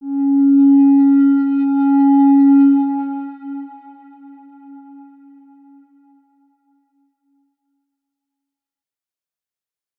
X_Windwistle-C#3-pp.wav